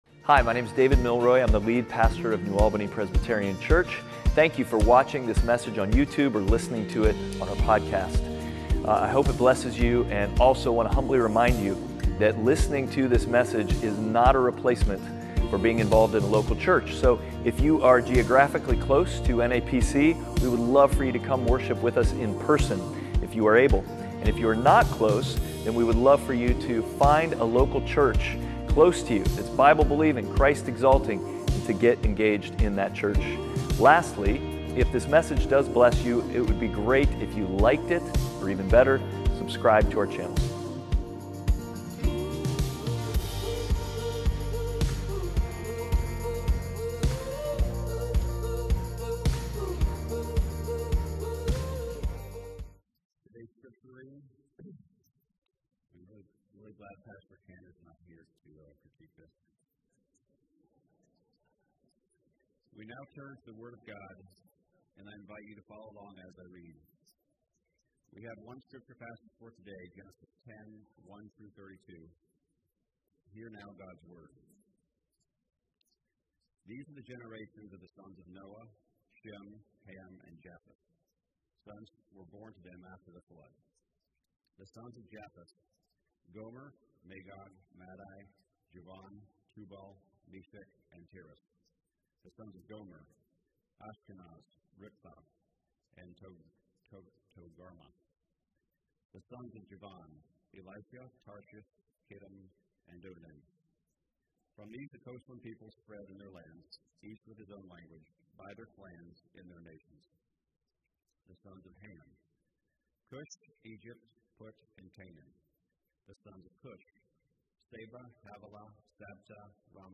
Passage: Genesis 10:1-32 Service Type: Sunday Worship